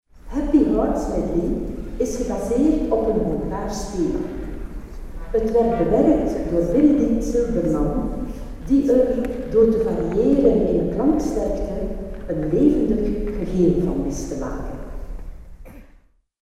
Presentatie